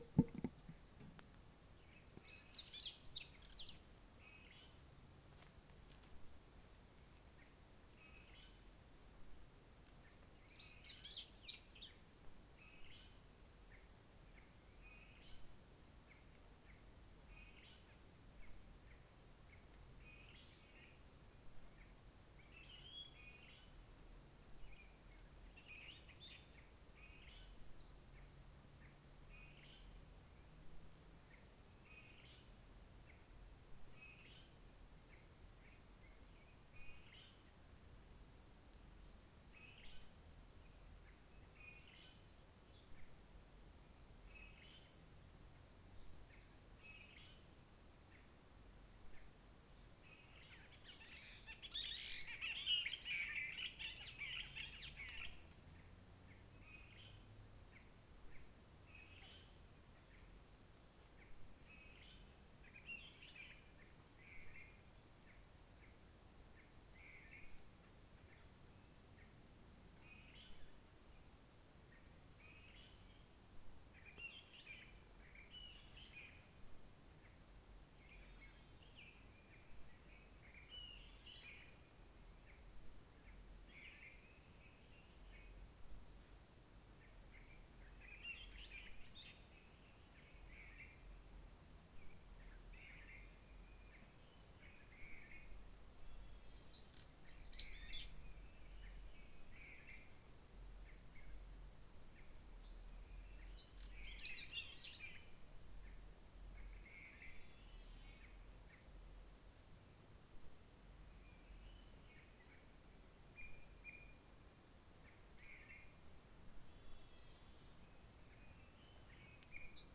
dans la Nature Roche Merveilleuse sur la Route Forestière (regroupement des vidéos).
Niveau sonore faible: Merles et tec tec Roche Merveilleuse, route forestière abri désordre-la-ville.